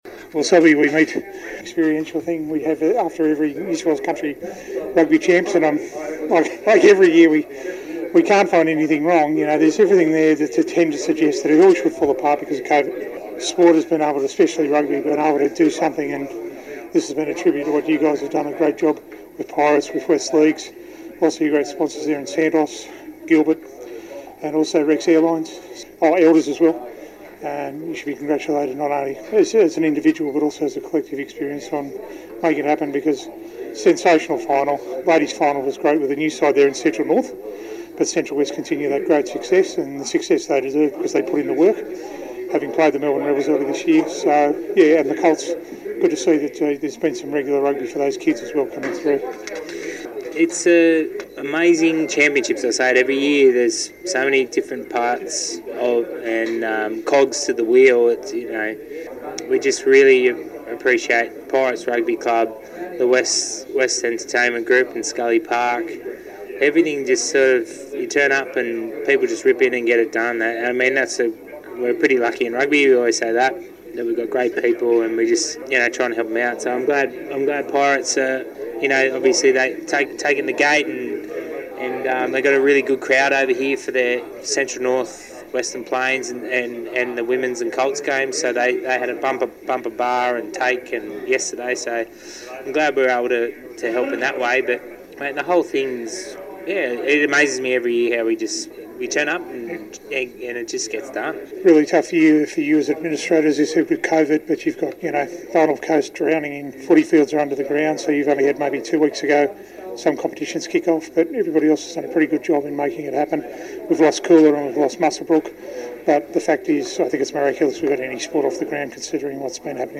at last weekends Country Championships in Tamworth.